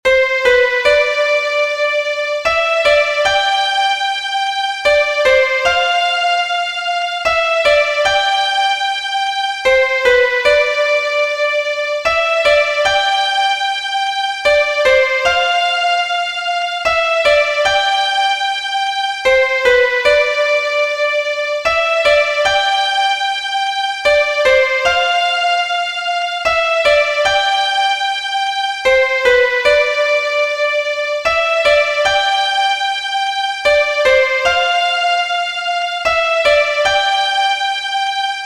ピアノ音とストリングスのシンプルな着信音です。